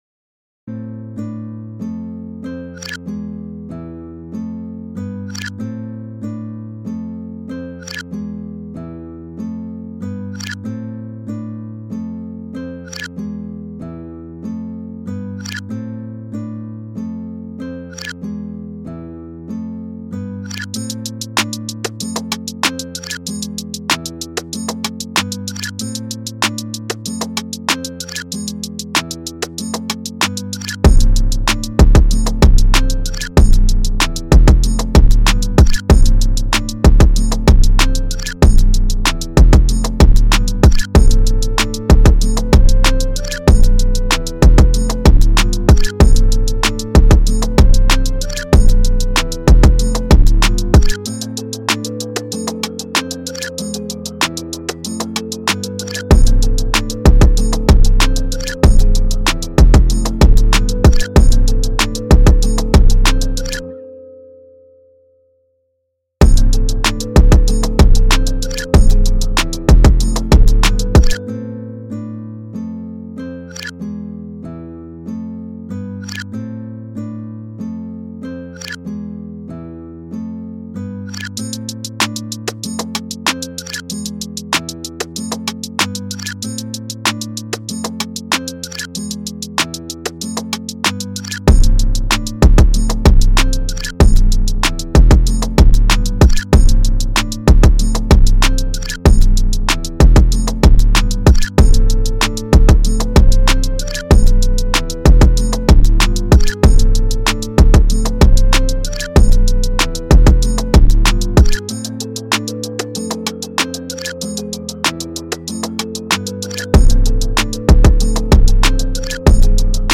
音色试听
电音采样包